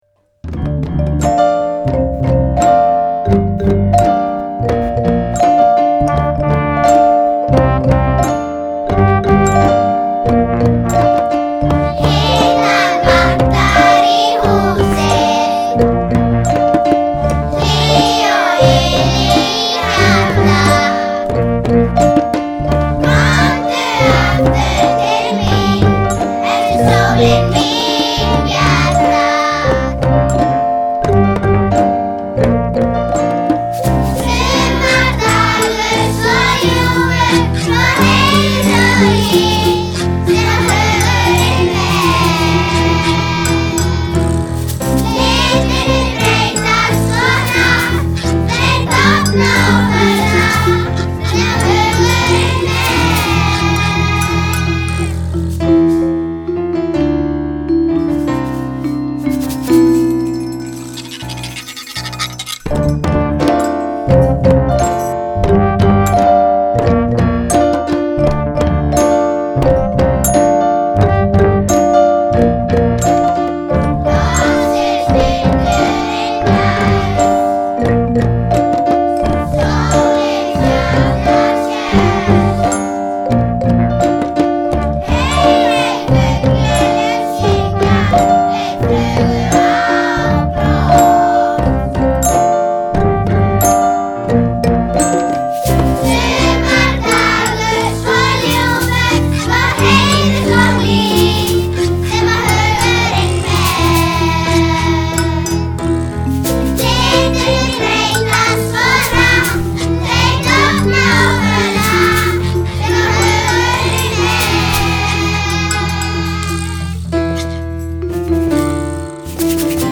Unnið var að upptökum í tónmenntatímunum í desember og janúar. Nemendur sáu bæði um hljóðfæraleik og söng.